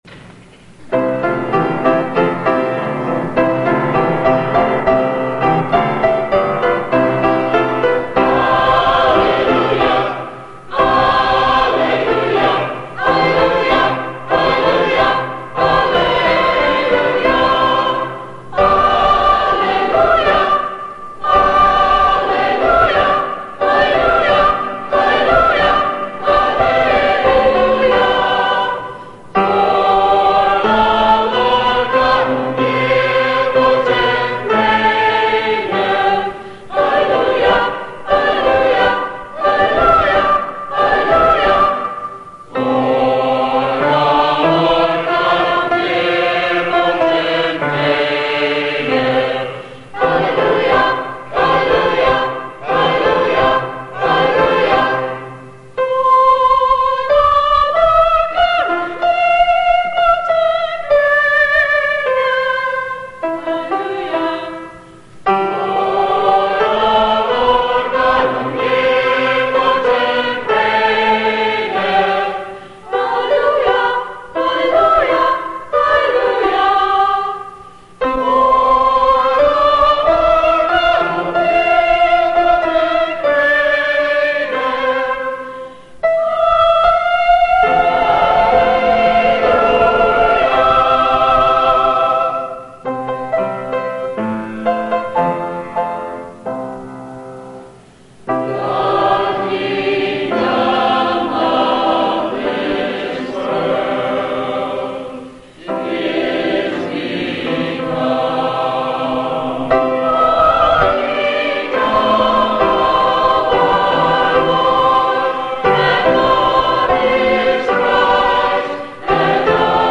Index of /Chicago_Discourses/1980_Chicago_Memorial_Day_Convention
Song_Service_-_Hallelujah_Chorus.mp3